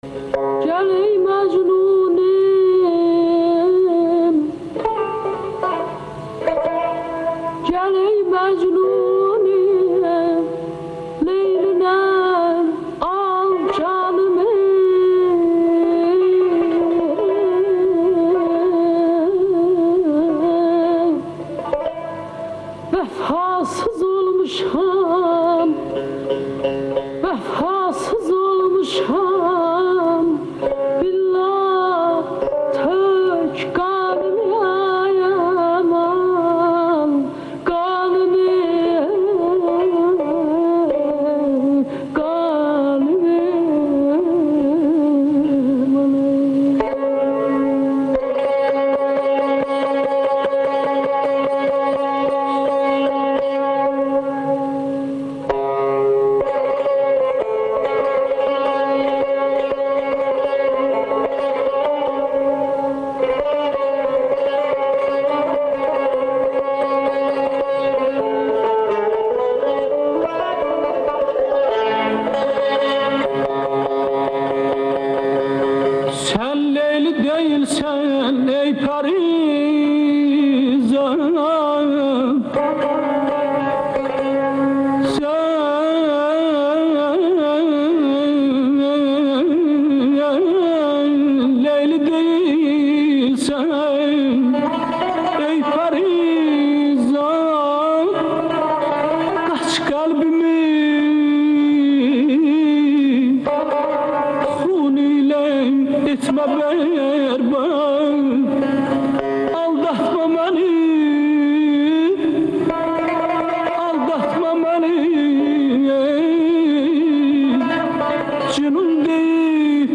Song performed by Azeri singer Rubabe Muradova ("Leyli and Majnun", opera)
Azerbaijan - Song performed by Azeri singer Rubabe Muradova